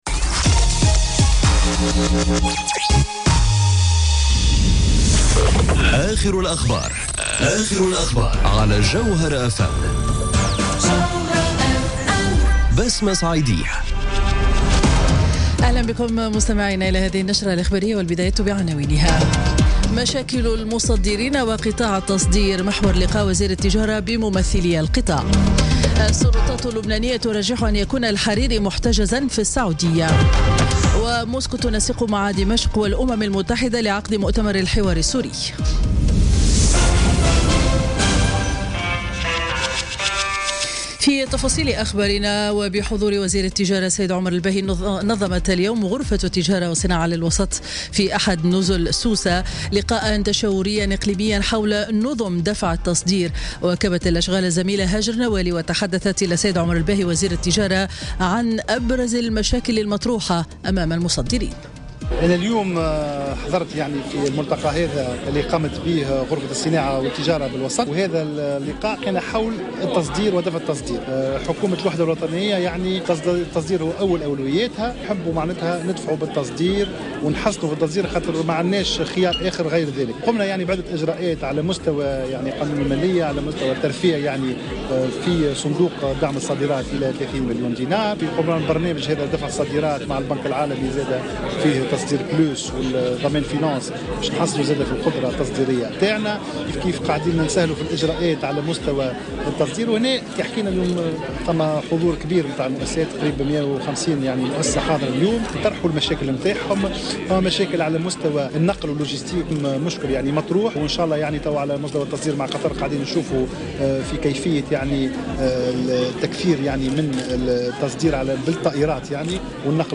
نشرة أخبار منتصف النهار ليوم الخميس 9 نوفمبر 2017